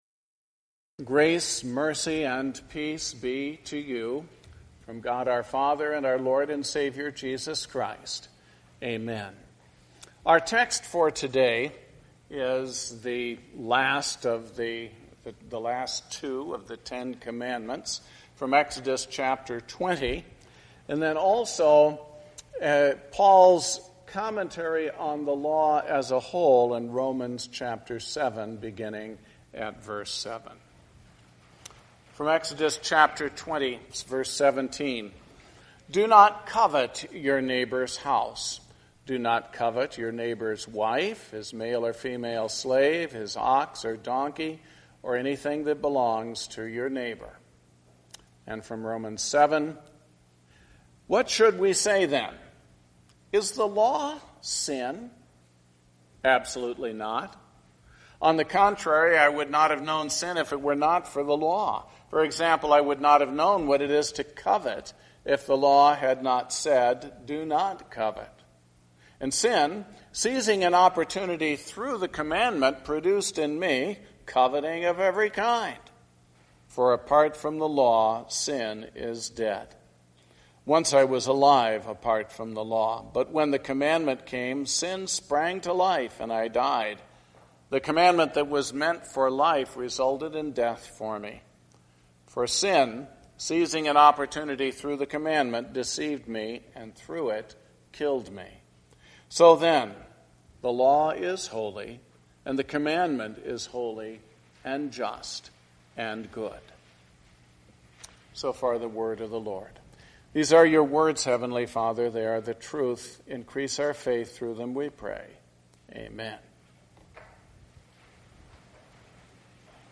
Sermon based on the Ninth and Tenth Commandment: Exodus 20:17 and Romans 7:7-12.